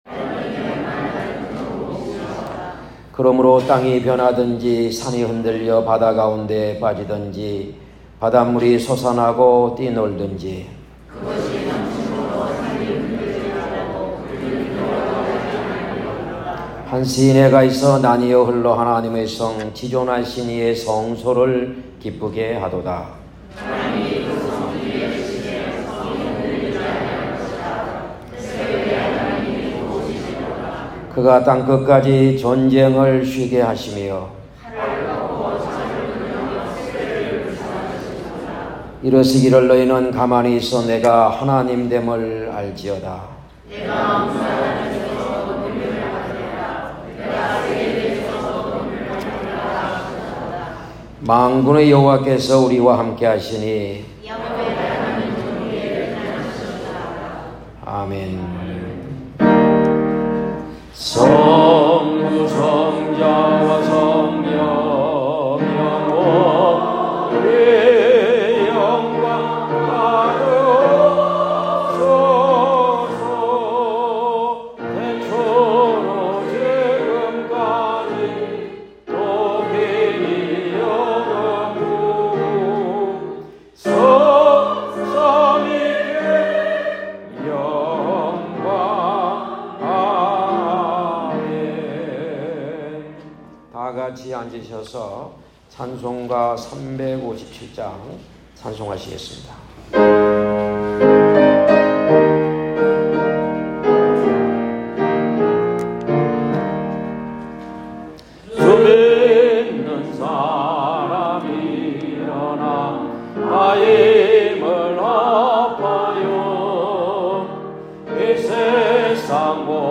2023년 8월 27일 주일설교